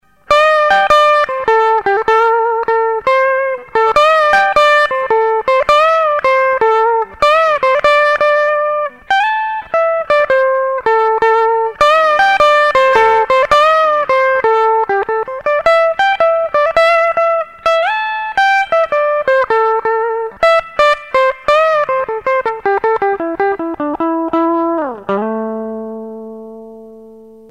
crunchytubereverb.mp3